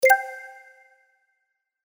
UI_Click.mp3